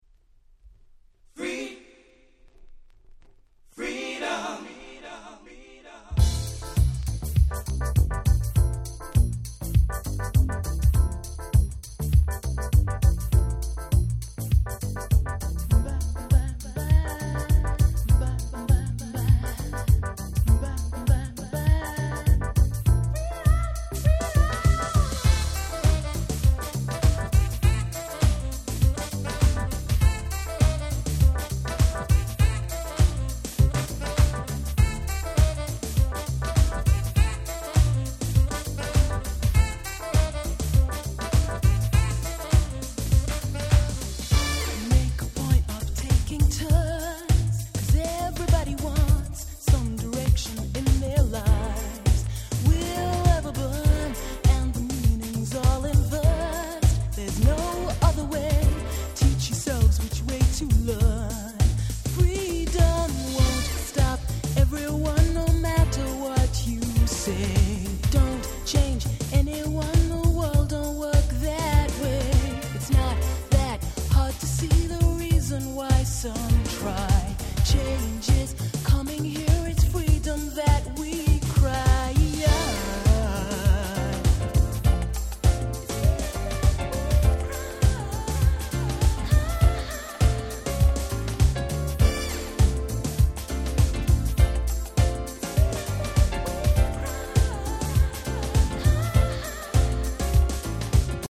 91' Nice UK Soul/R&B !!